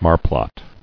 [mar·plot]